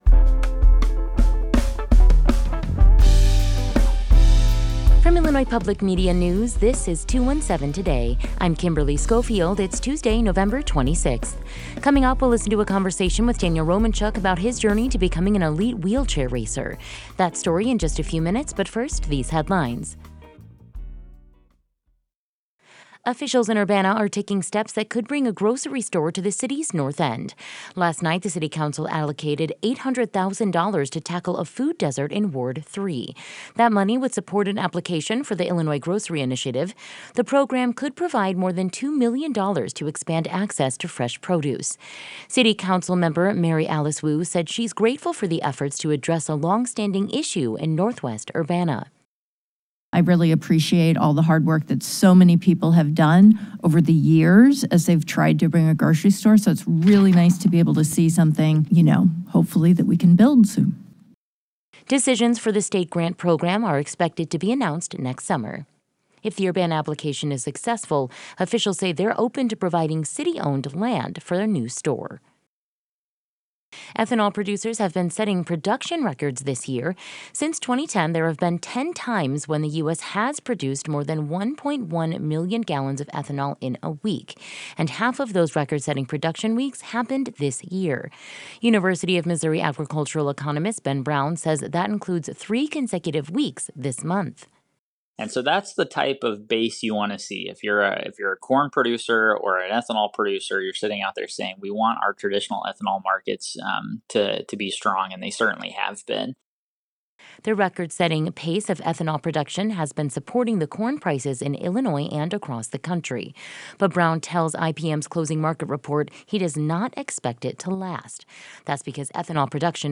In today's deep dive, we’ll listen to a conversation with Daniel Romanchuk about his journey to becoming an elite wheelchair racer.